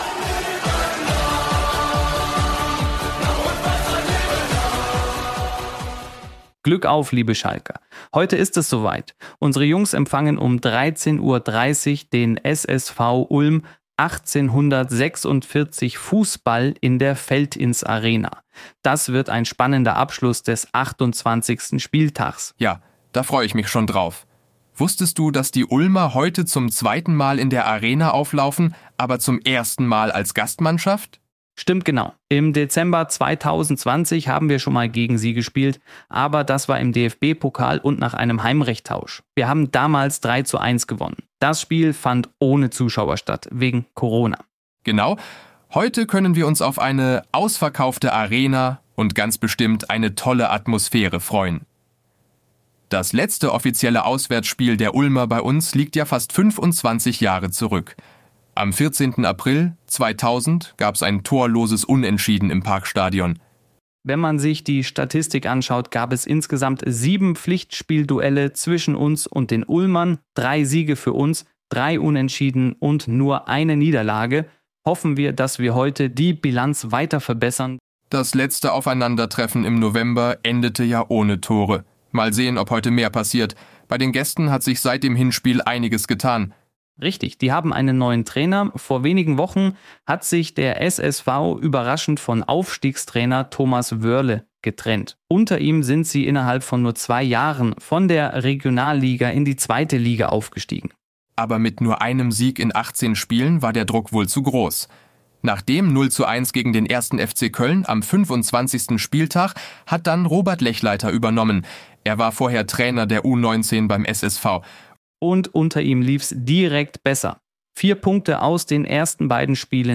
Beim offiziellen Schalke 04 Podcast können sich Fans auf spannende und launige Gespräche mit aktuellen Spielern, Ex-Profis, Verantwortlichen und weiteren Protagonisten aus dem Vereinsumfeld freuen.
In jeder Podcast-Folge wird der Gast zudem mit Sprachnachrichten und persönlichen Fragen aktueller und ehemaliger Weggefährten überrascht.